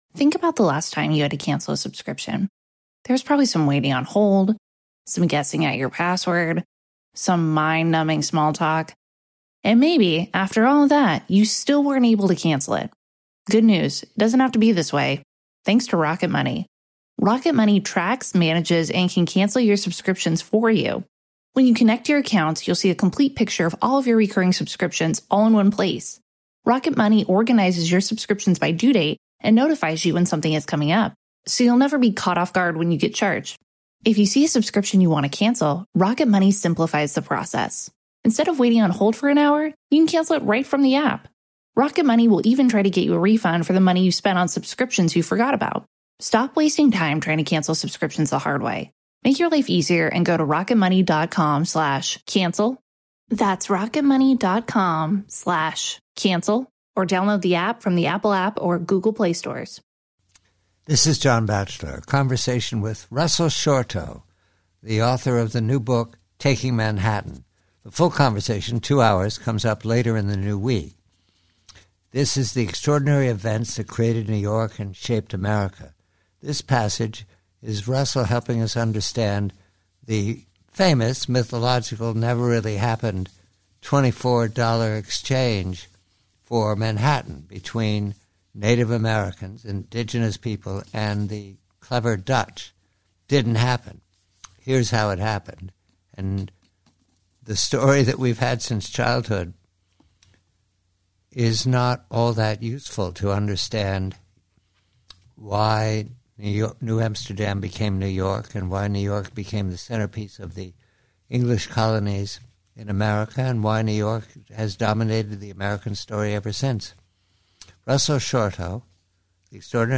PREVIEW: Conversation with Russell Shorto, author of the new "Taking Manhattan," regarding the legendary and fictional event when Dutch settlers supposedly bought Manhattan Island for $24 of trinkets.